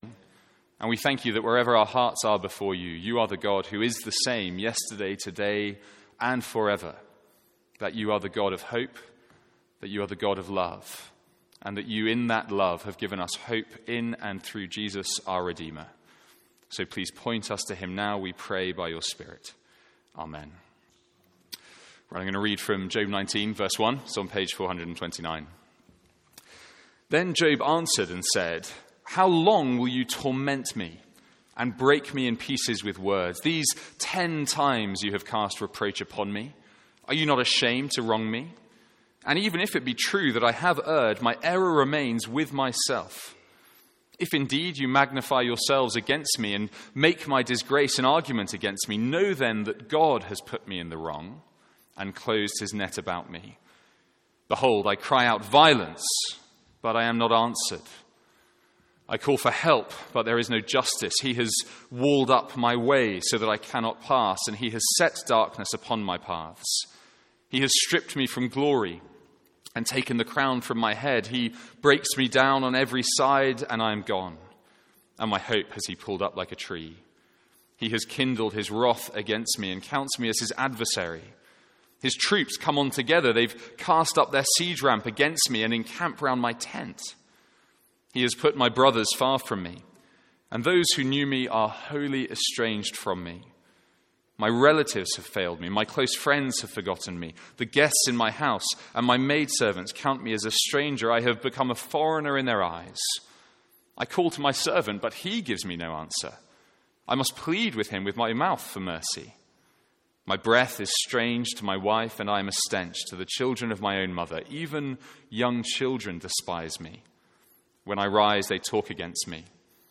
Sermons | St Andrews Free Church
From our morning series in Job.